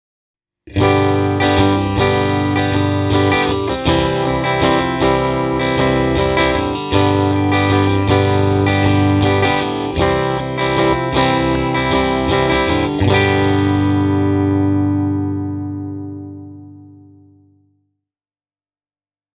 TOOB 12J soi selvästi ilmaavammin, kun taas TOOB 12R:ssä on hieman ryhdikkäämpi luonne ja enemmän potkua bassorekisterissä.
PUHDAS STRATO
the-toob-j-e28093-clean-strat.mp3